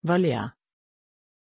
Pronunciation of Valya
pronunciation-valya-sv.mp3